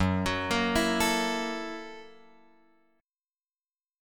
F#7#9 chord {2 x 4 3 5 5} chord